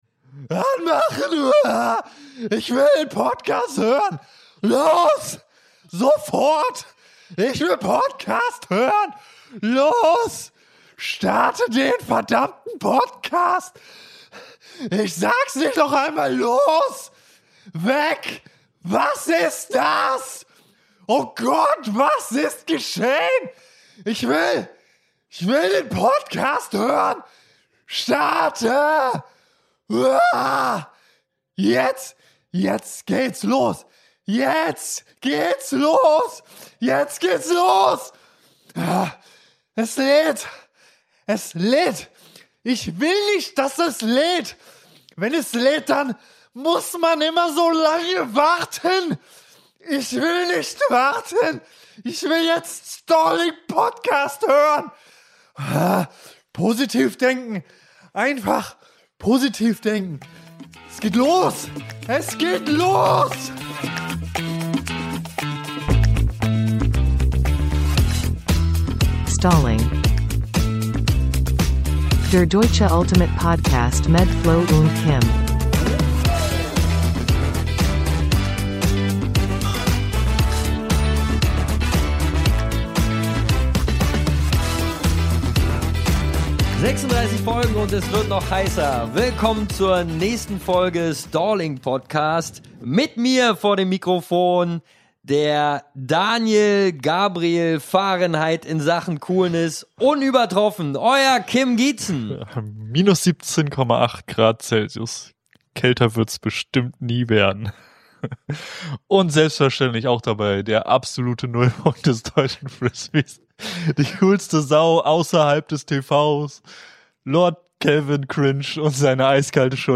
Beschreibung vor 1 Jahr Auf deiner Stirn steht Ultimate, zwei Gangster sind am Mikrofon Was wollt ihr tun?